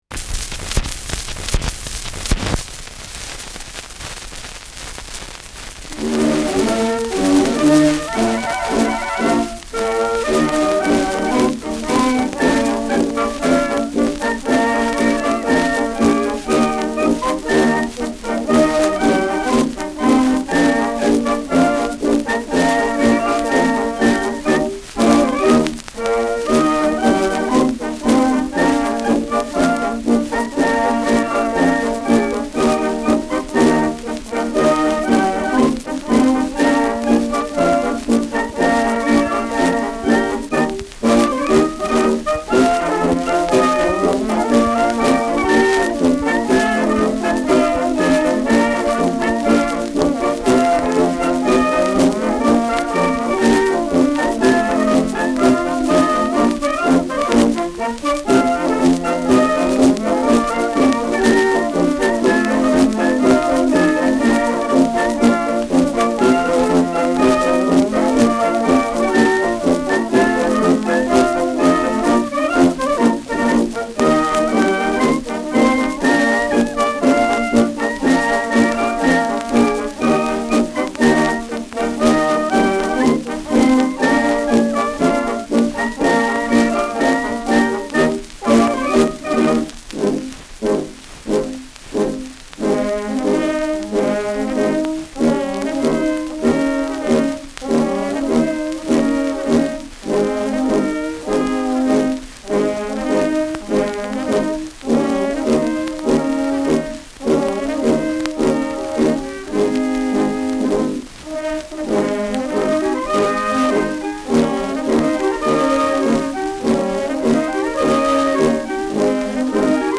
Schellackplatte
Abgespielt : Erhöhter Klirrfaktor : Nadelgeräusch : Gelegentlich leichtes Knacken : Leichtes Störgeräusch durch Tonarmbewegung im unteren Frequenzbereich
Stadtkapelle Fürth (Interpretation)
[Nürnberg] (Aufnahmeort)
Schützenkapelle* FVS-00006